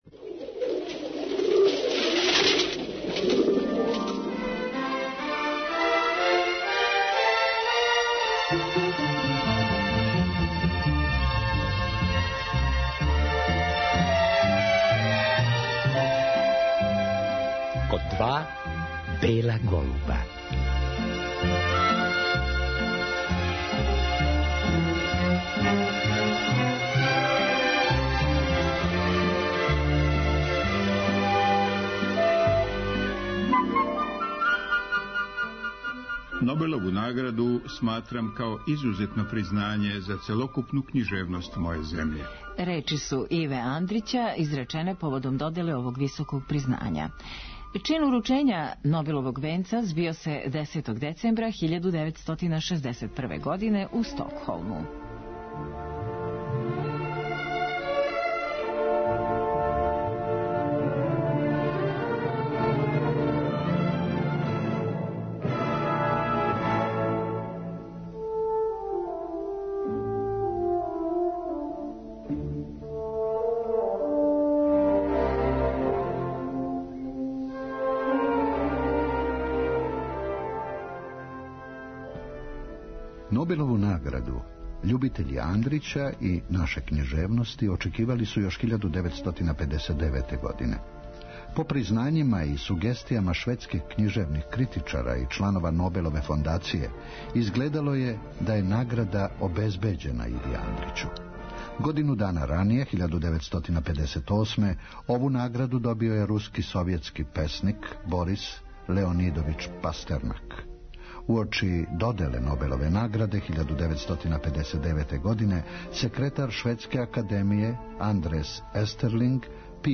Чућемо и како је Иво Андрић реаговао на вест да је ова награда додељена њему, и шта је изјавио кад су му то саопштили.
У емисији ћемо чути и делове беседе коју је Иво Андрић изговорио, на француском језику, приликом уручења Нобелове награде.